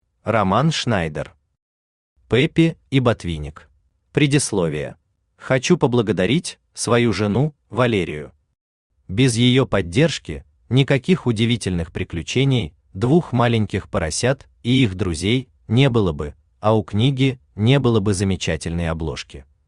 Аудиокнига Пеппе и Ботвинник | Библиотека аудиокниг
Aудиокнига Пеппе и Ботвинник Автор Роман Шнайдер Читает аудиокнигу Авточтец ЛитРес.